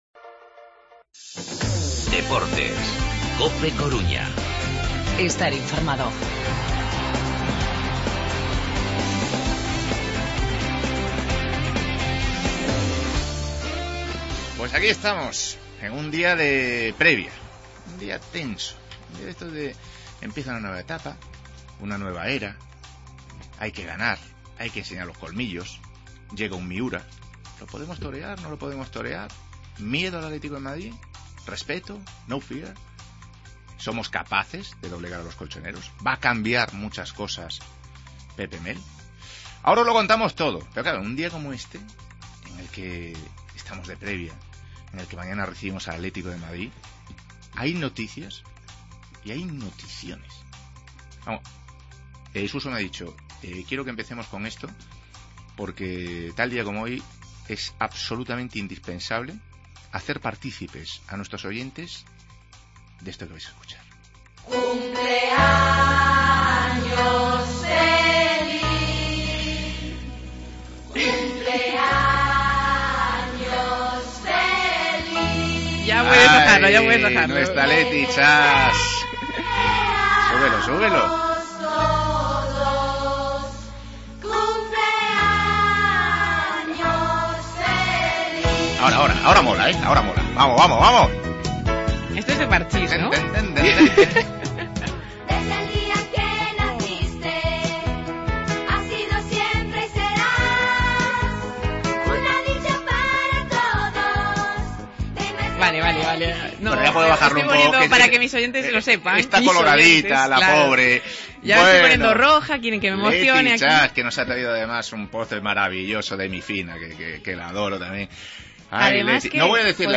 Redacción digital Madrid - Publicado el 01 mar 2017, 16:11 - Actualizado 18 mar 2023, 18:41 1 min lectura Descargar Facebook Twitter Whatsapp Telegram Enviar por email Copiar enlace Última hora del Deportivo-Atlético de Madrid, palabras de Pepe Mel y Germán Lux. Debate: ¿Acierta el Depor con el fichaje de Pepe Mel?